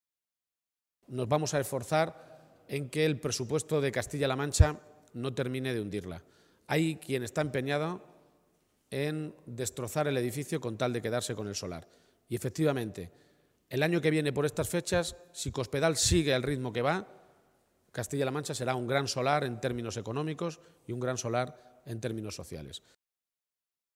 El secretario regional del PSOE de Castilla-La Mancha, Emiliano García-Page, ha participado esta mañana en el comité provincial del PSOE de Guadalajara en la localidad de Alovera.
Cortes de audio de la rueda de prensa